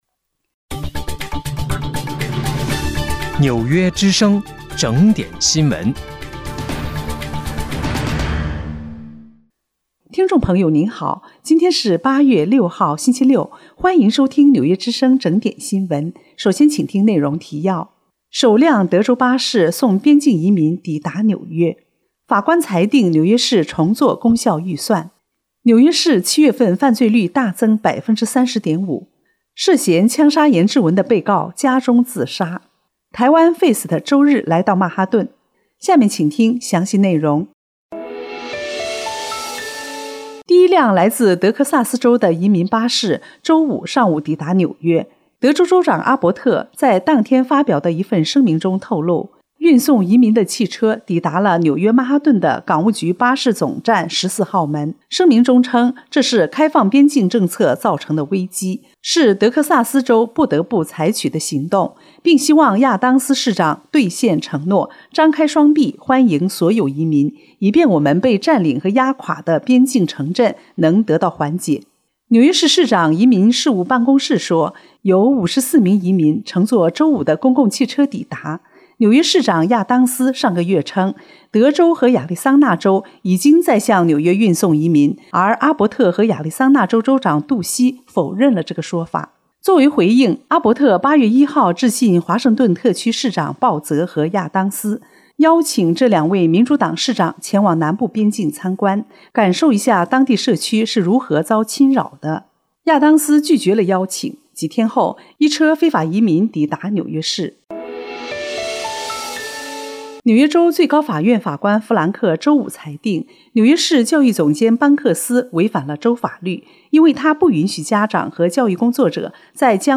8月6日（星期六）纽约整点新闻